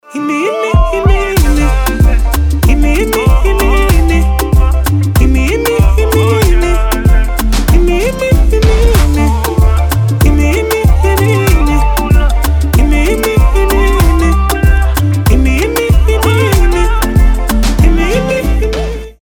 позитивные
забавные
заводные
RnB
легкие
Французский афробит